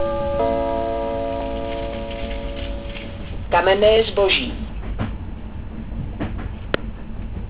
Přidávám hlášení z vlaku linky S2. Omlouvám se za mírně horší kvalitu nahrávek..